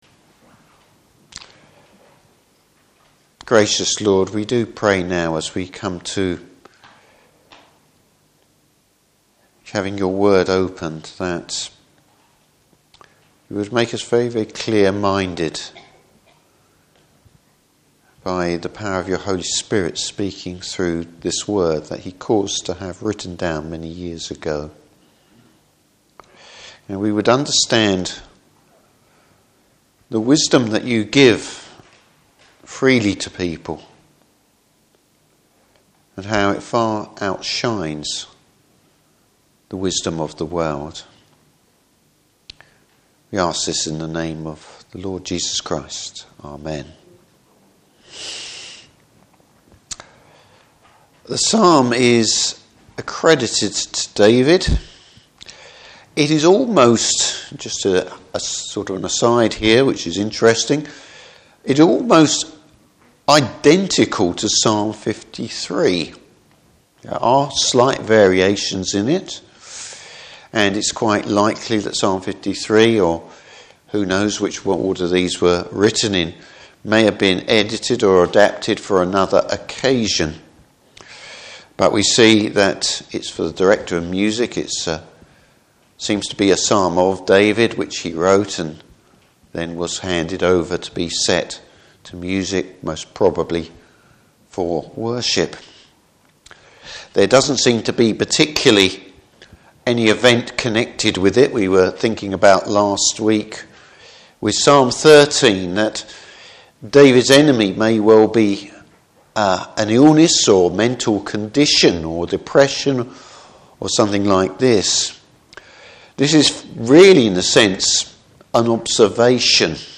Service Type: Evening Service How the supposed wisdom of the world just doesn’t cut it!